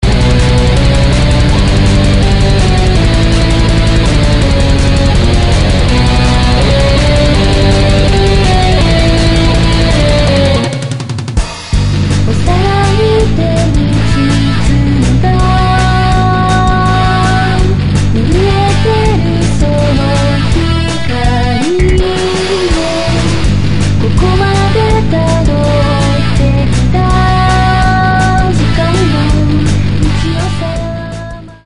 ゲームソング・メタル・アンソロジー